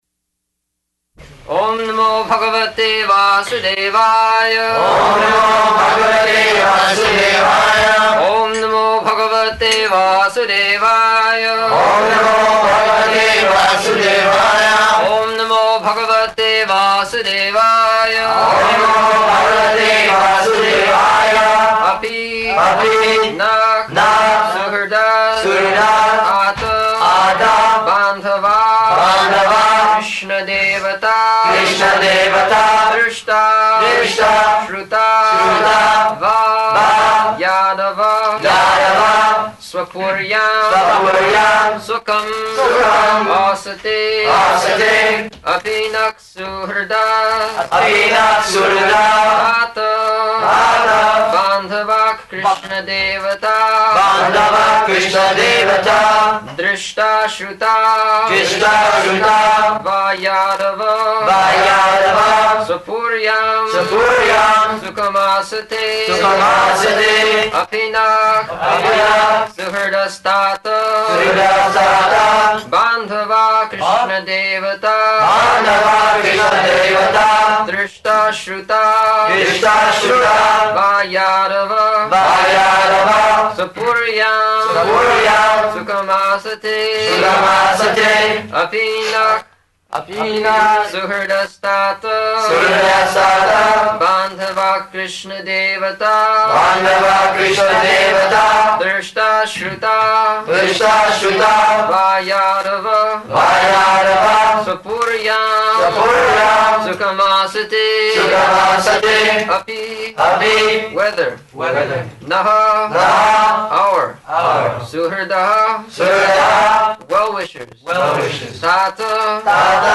June 2nd 1974 Location: Geneva Audio file
[Prabhupāda and devotees repeat] [leads chanting of verse, etc.]